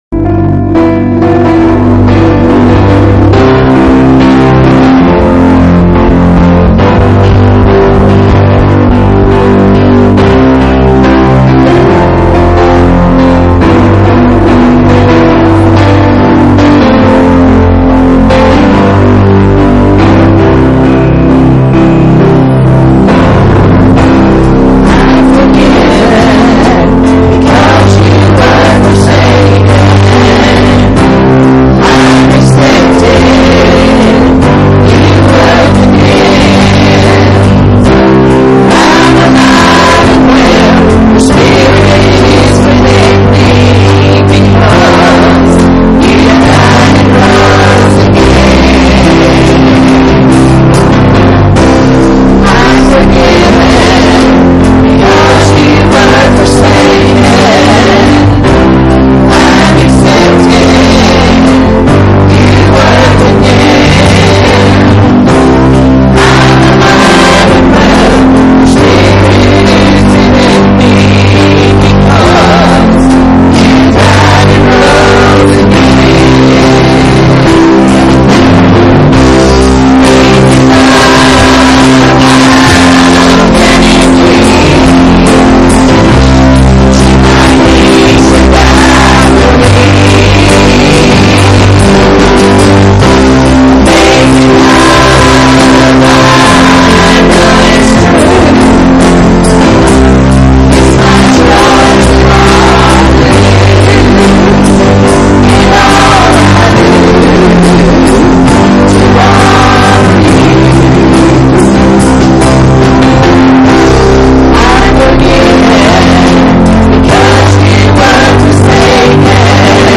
Psalms 51:1-12 Service Type: Sunday Morning Services Topics